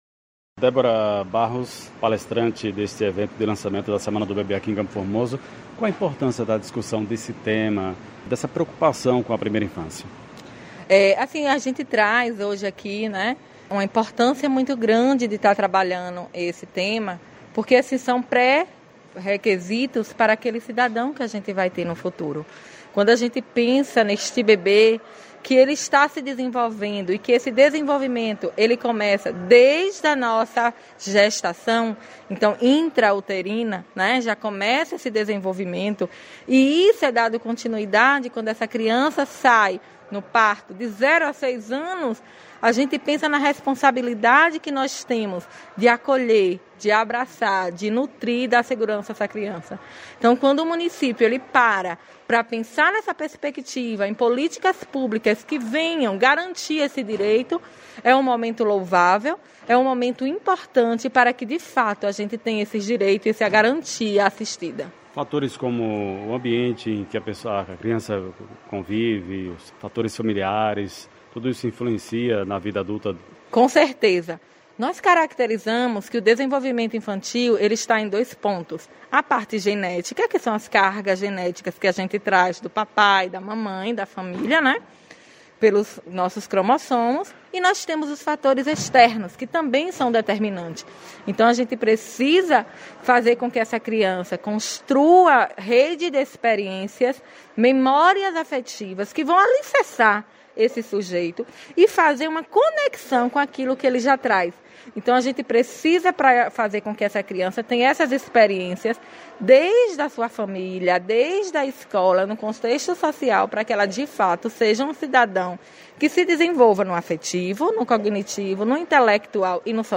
Reportagem: prefeitura de CFormoso lança projeto Semana do Bebê